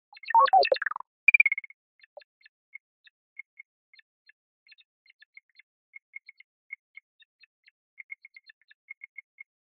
Robot Movements Mechanical beeps, servo noises "Synthetic dashboard beeps with smooth electronic tones"